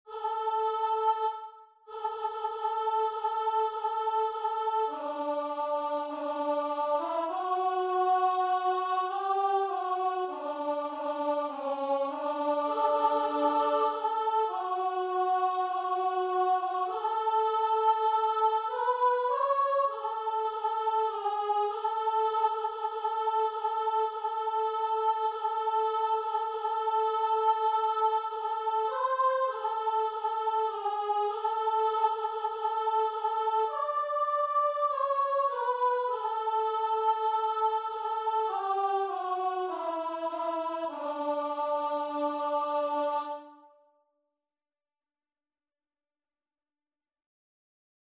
Christian
4/4 (View more 4/4 Music)
Classical (View more Classical Guitar and Vocal Music)